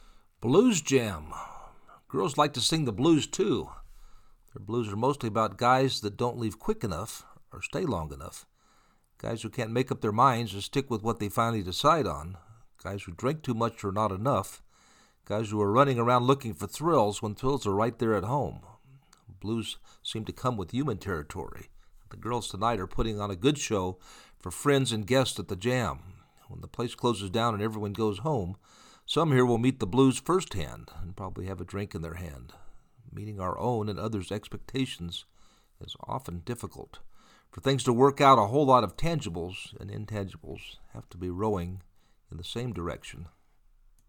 Blues Jam Girls Night Out
Girls like to sing the blues too.
Blues seem to come with human territory and the girls, tonight, are putting on a good show for friends and guests at the jam.
blues-jam.mp3